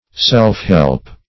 Self-help \Self`-help"\, n.